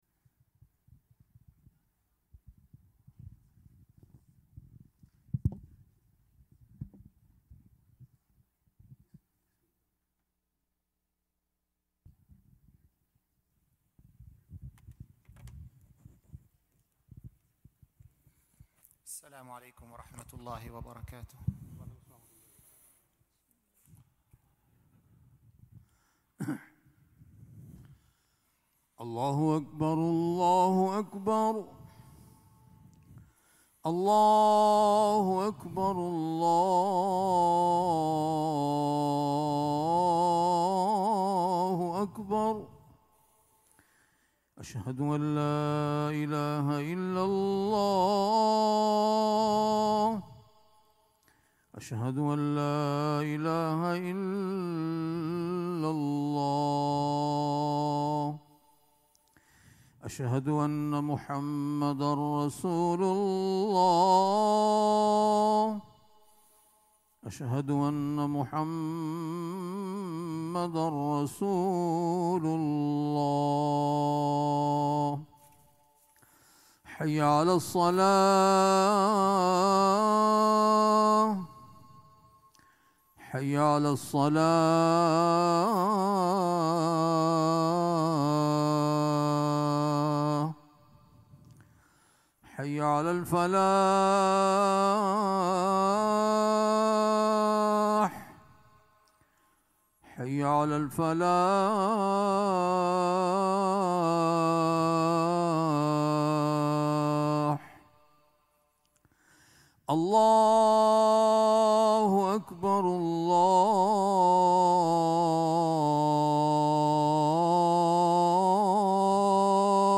Friday Khutbah - "The Human Intellect"